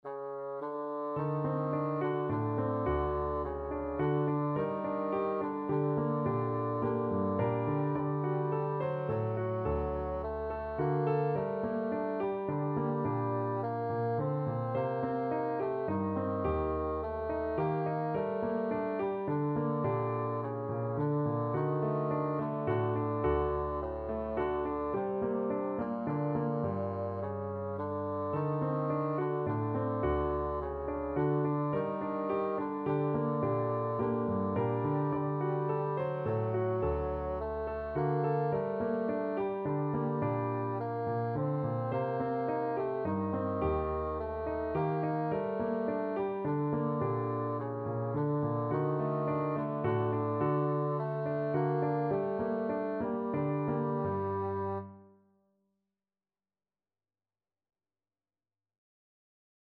Bassoon
Slow Waltz = c. 106
G minor (Sounding Pitch) (View more G minor Music for Bassoon )
3/4 (View more 3/4 Music)
Traditional (View more Traditional Bassoon Music)
Russian